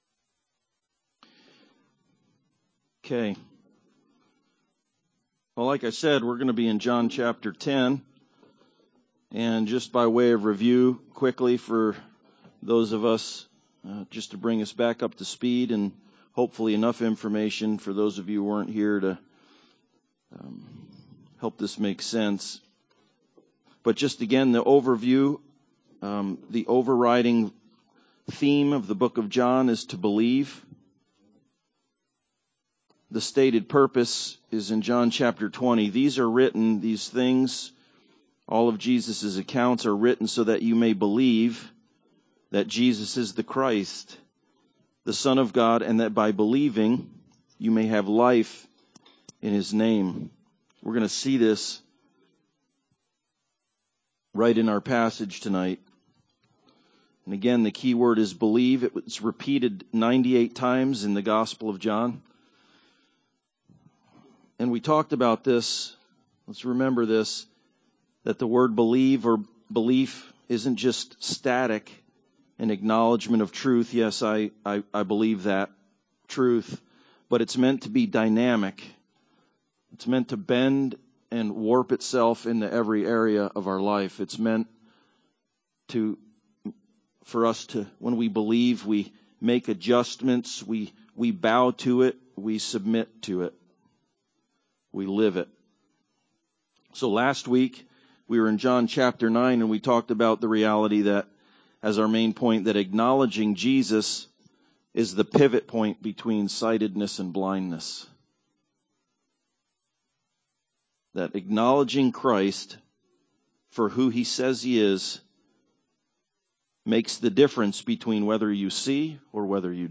Johm 10:1-21 Service Type: Sunday Service Bible Text